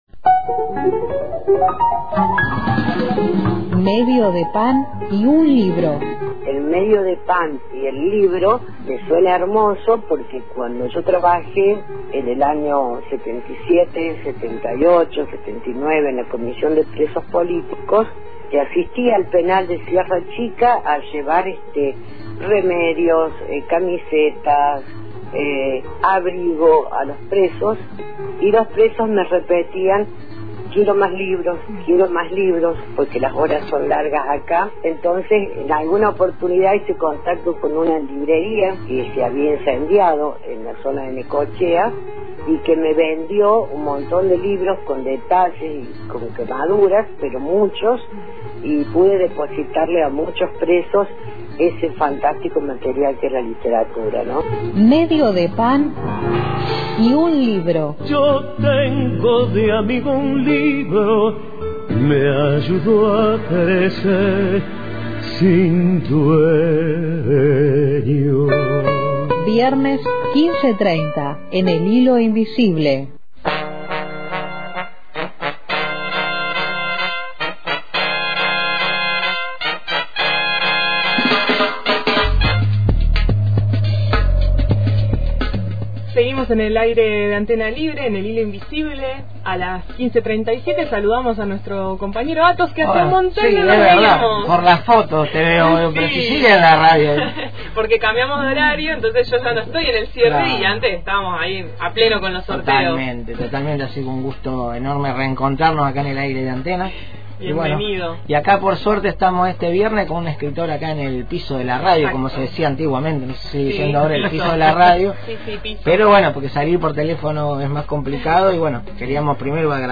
Además de compartir un fragmento de su obra al aire, el autor habló de las dificultades que atravesó para sostener su escritura en un entorno conservador y reivindicó su camino autodidacta. La entrevista dejó ver una trayectoria construida a fuerza de constancia, sensibilidad y una decisión firme de seguir escribiendo desde una voz propia.